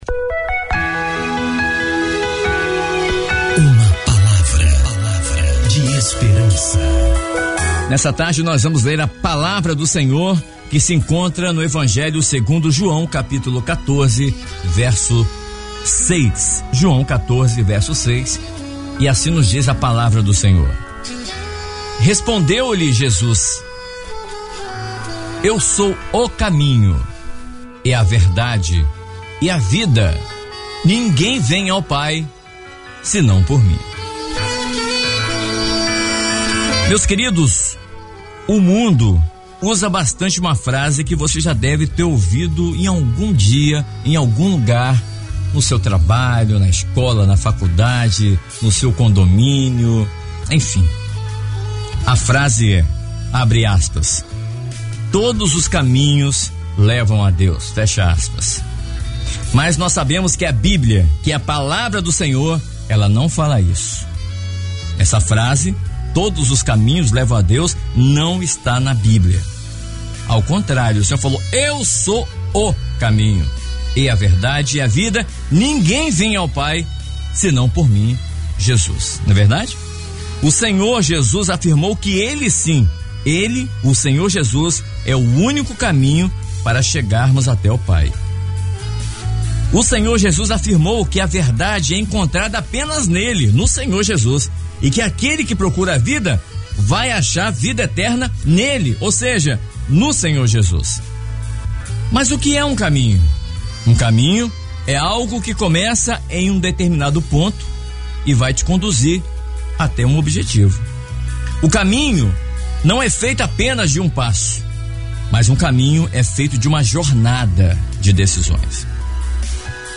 Mensagem transmitida no dia 11 de março de 2019, dentro do programa Vencendo Vem Jesus